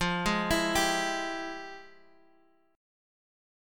FmM9 chord